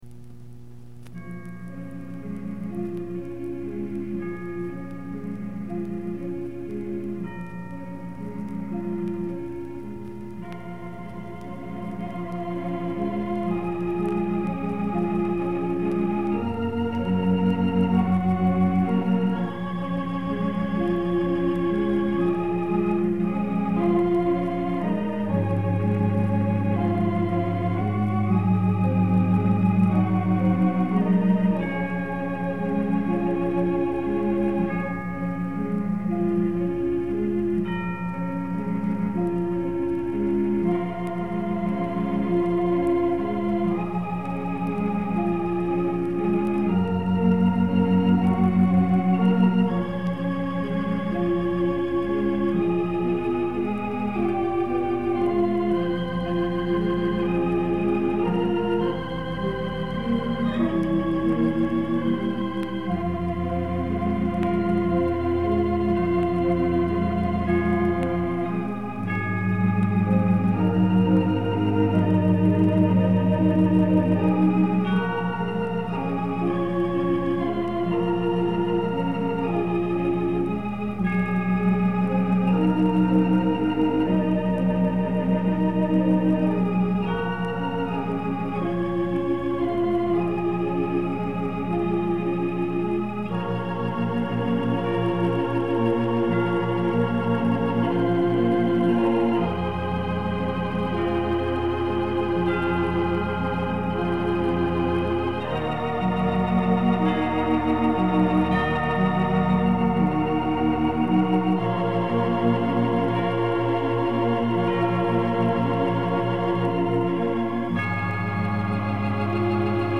His style on this album is somewhat unique.